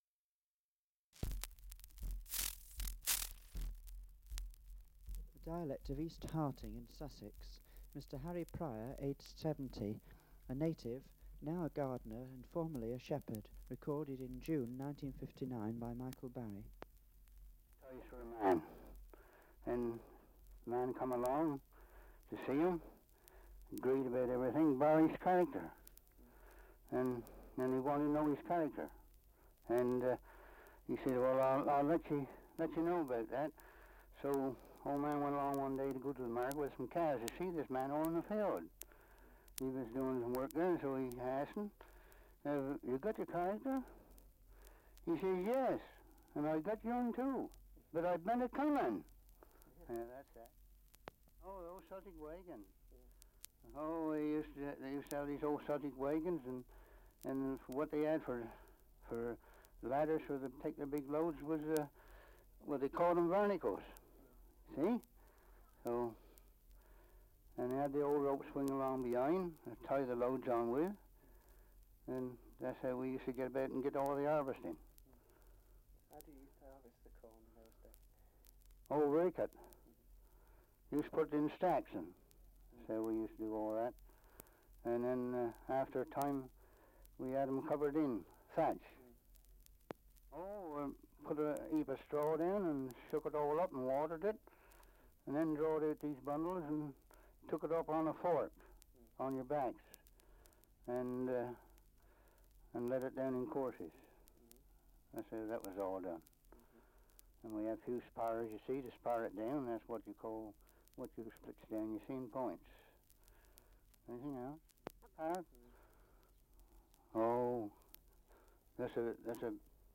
Survey of English Dialects recording in East Harting, Sussex. Survey of English Dialects recording in Sutton, Sussex
78 r.p.m., cellulose nitrate on aluminium